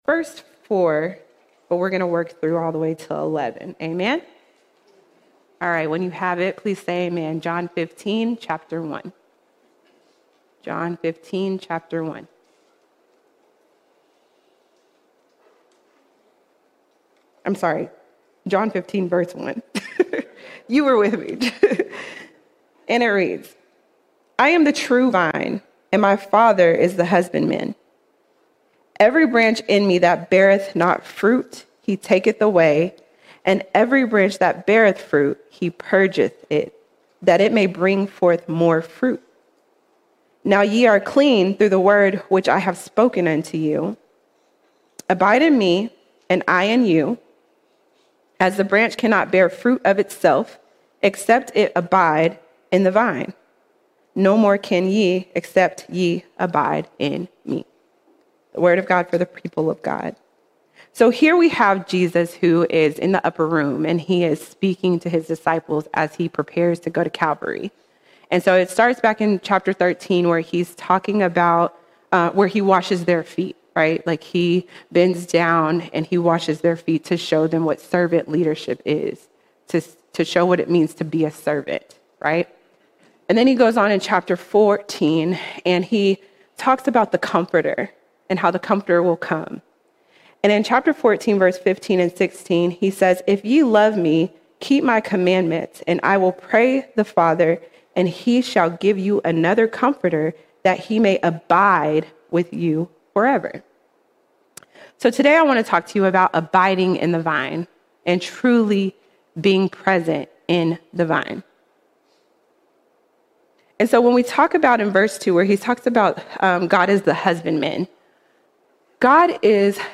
11 August 2025 Series: Sunday Sermons All Sermons Abide In The Vine Abide In The Vine Every believer is saved to bear fruit, and the only way to do so is by abiding in the true vine, Jesus Christ.